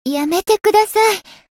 灵魂潮汐-薇姬娜-互动-不耐烦的反馈1.ogg